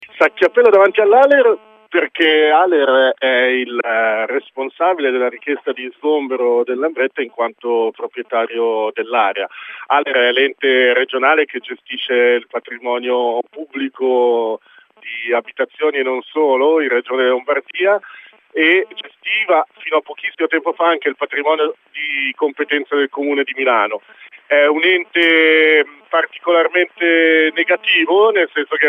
La corrispondenza con Radio Onda d’Urto sull’azione all’ALER.